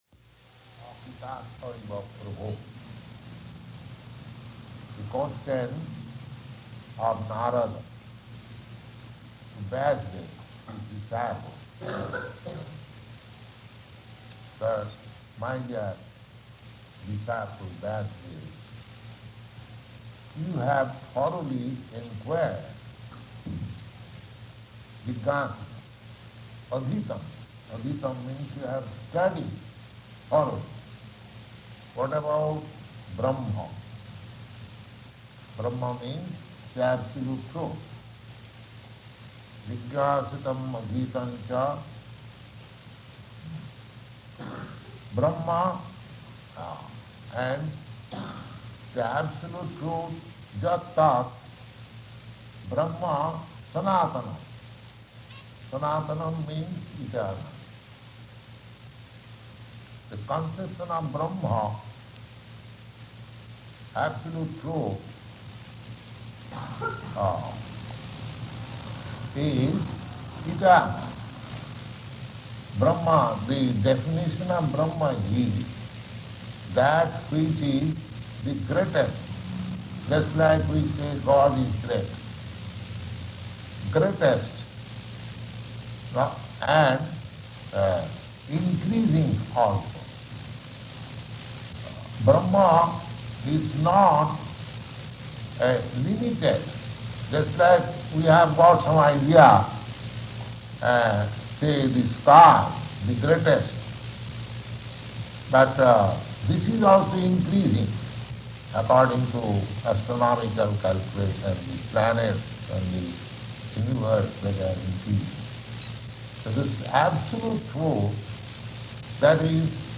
Śrīmad-Bhāgavatam 1.5.4 --:-- --:-- Type: Srimad-Bhagavatam Dated: January 12th 1968 Location: Los Angeles Audio file: 680112SB-LOS_ANGELES.mp3 [distorted audio] Prabhupāda: ... akṛtārtha iva prabho [ SB 1.5.4 ] .